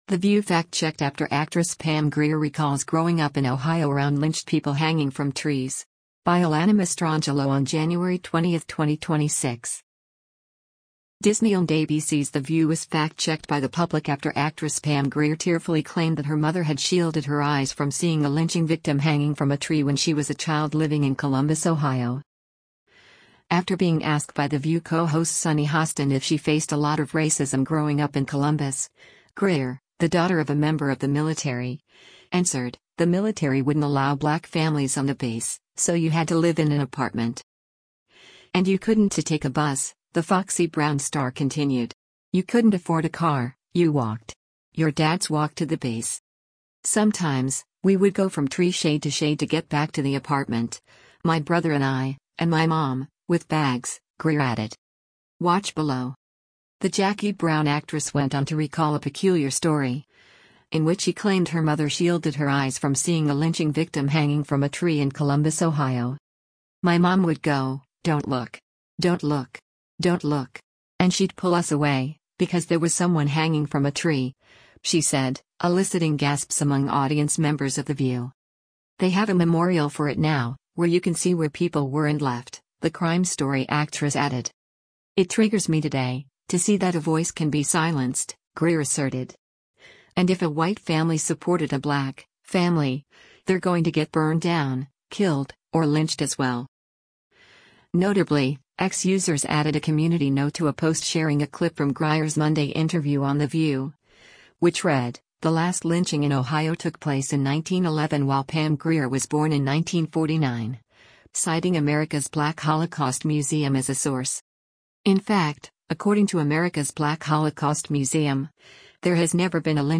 Disney-owned ABC’s The View was fact-checked by the public after actress Pam Grier tearfully claimed that her mother had shielded her eyes from seeing a lynching victim “hanging from a tree” when she was a child living in Columbus, Ohio.
“My mom would go, ‘Don’t look! Don’t look! Don’t look!’ and she’d pull us away, because there was someone hanging from a tree,” she said, eliciting gasps among audience members of The View.